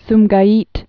(smgī-ēt)